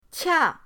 qia4.mp3